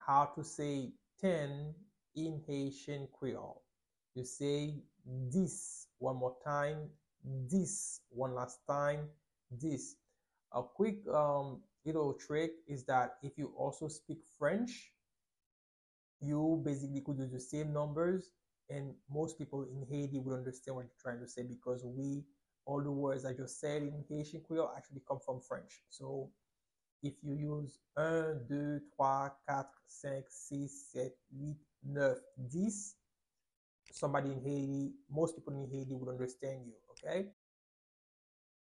Listen to and watch “Dis” audio pronunciation in Haitian Creole by a native Haitian  in the video below:
13.How-to-say-Ten-in-Haitian-Creole-–-Dis-pronunciation-.mp3